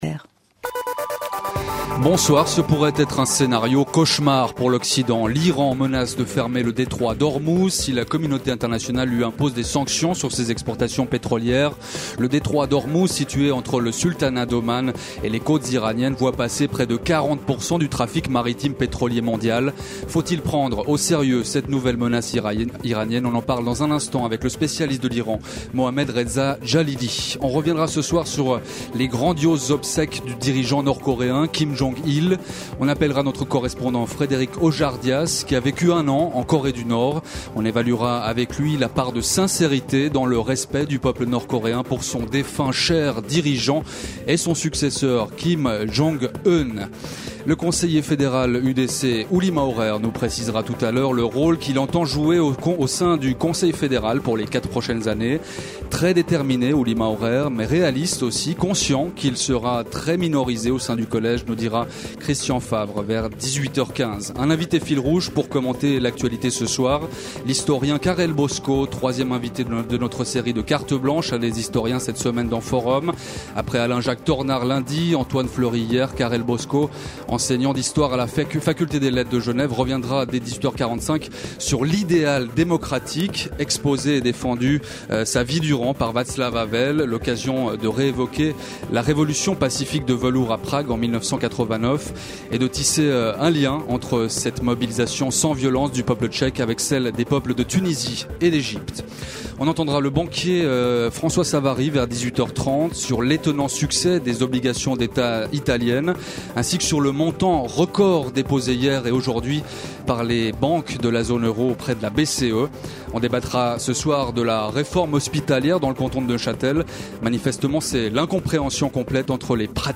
7 jours sur 7, Forum questionne en direct les acteurs de l’actualité, ouvre le débat sur les controverses qui animent la vie politique, culturelle et économique.